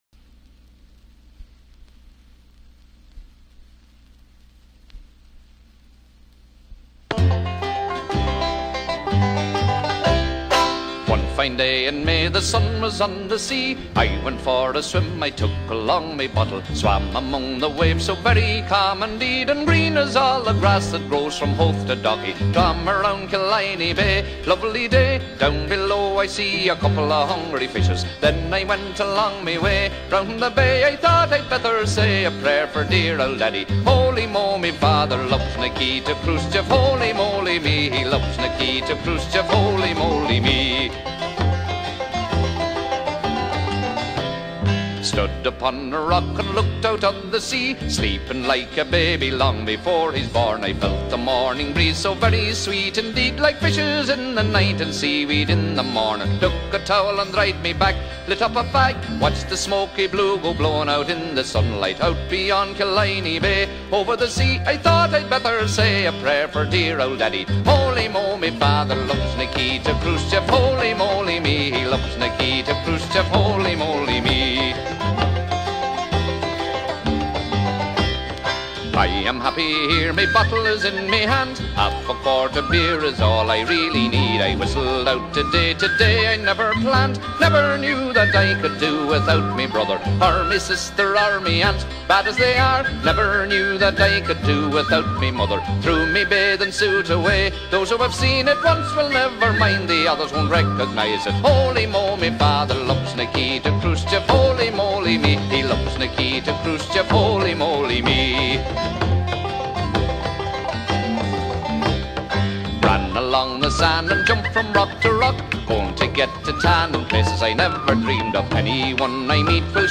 Известный ирландский фолк-сингер и игрец на банджо